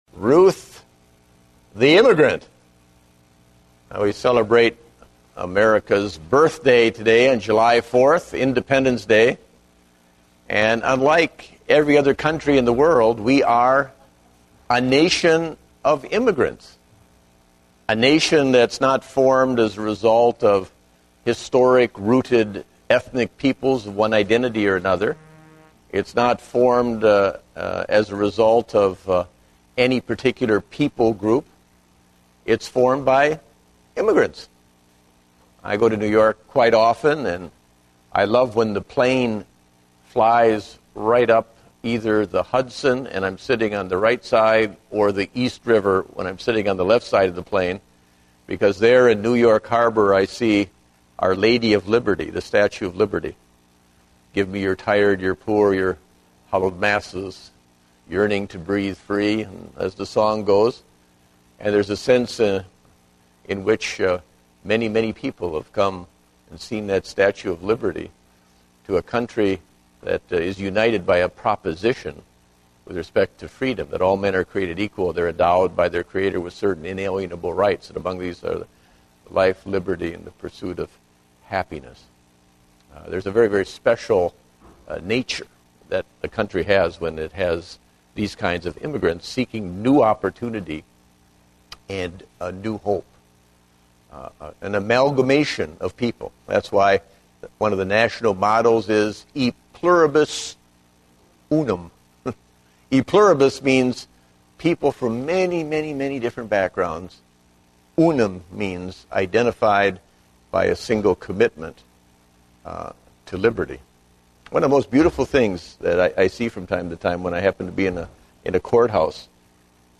Date: July 4, 2010 (Adult Sunday School)